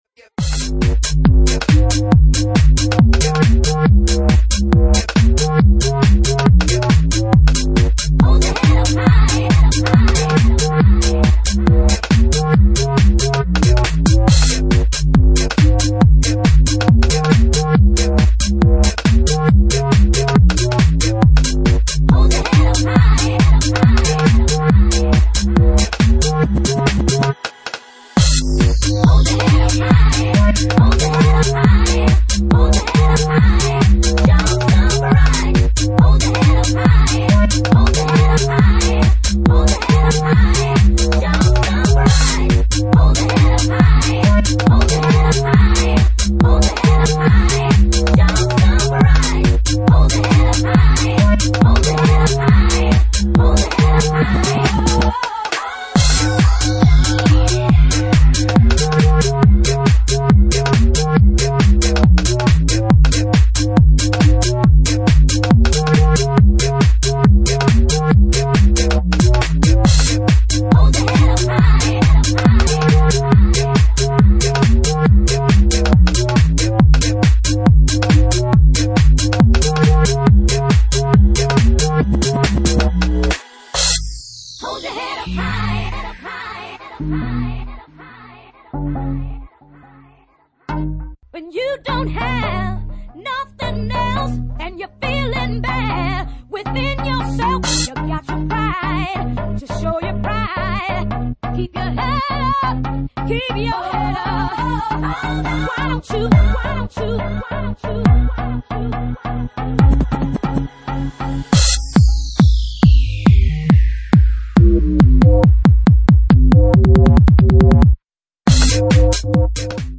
Genre:Bassline House
Bassline House at 138 bpm